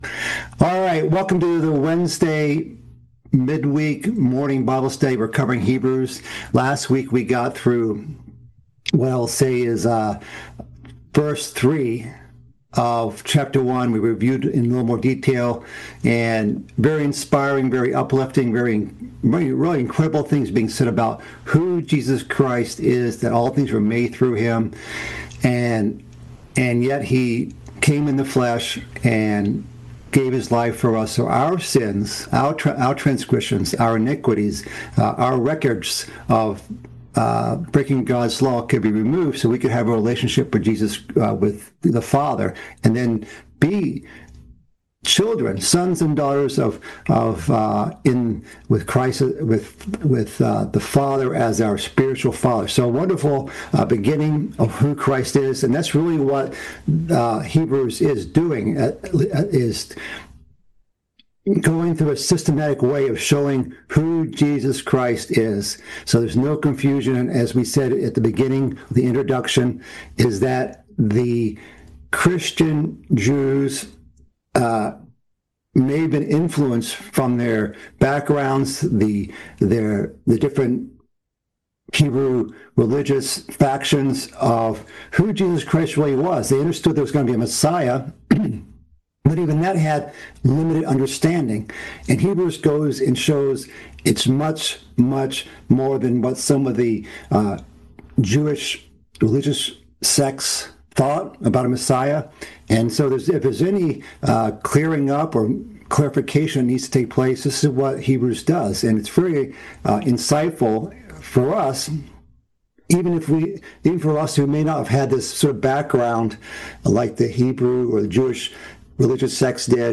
The fourth part in a series of mid-week Bible studies, covering the book of Hebrews. This session continues in the first chapter of Hebrews.